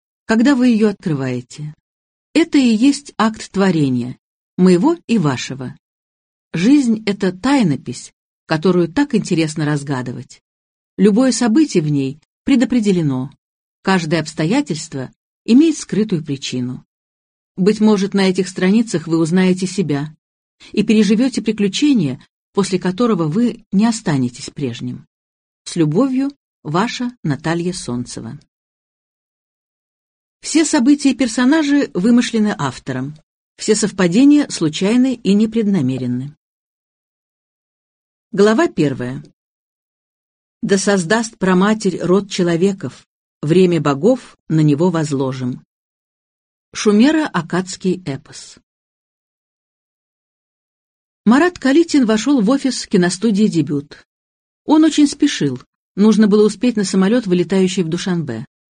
Аудиокнига В горах ближе к небу | Библиотека аудиокниг